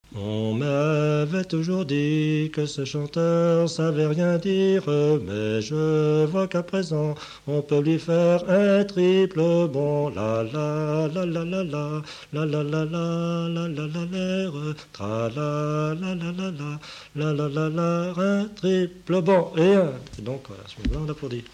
Saint-Michel-en-l'Herm
Chants brefs - A applaudir
Pièce musicale inédite